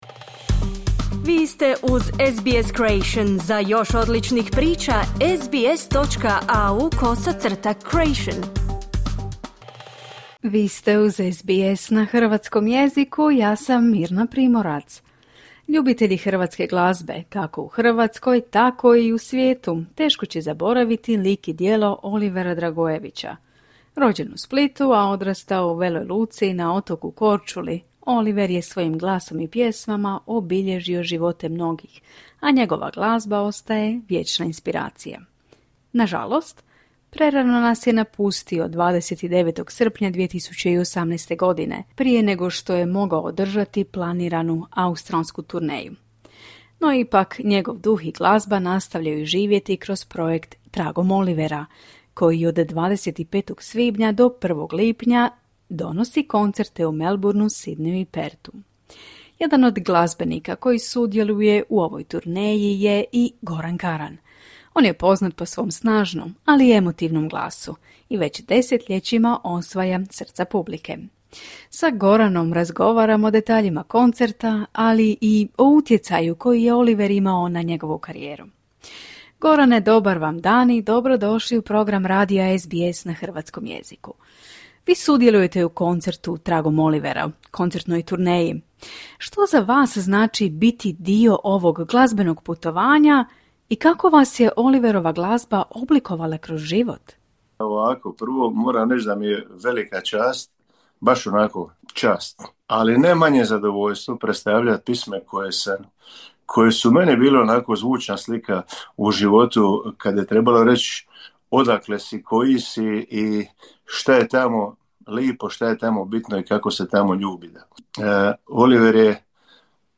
Goran za naš radio govori o koncertima, australskoj turneji, ali i o tome koliko i kako je Oliver utjecao na njegovu karijeru.